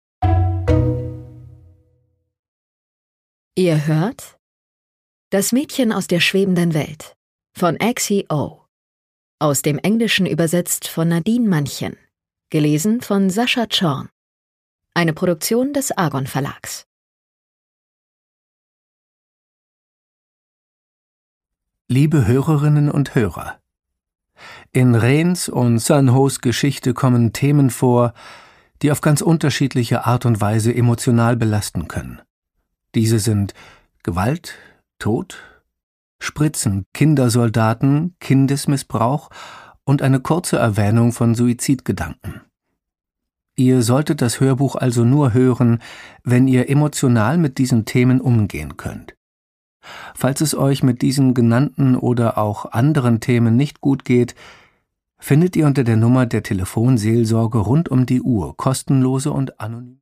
Produkttyp: Hörbuch-Download
fesselt mit seiner markanten Stimme von der ersten bis zur letzten Minute